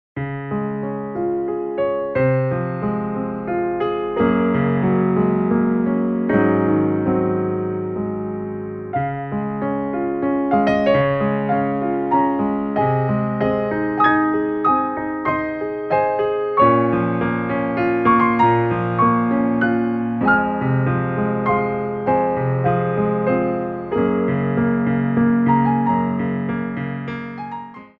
33 Inspirational Ballet Class Tracks
Pliés 1
3/4 (16x8)